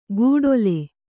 ♪ gūḍole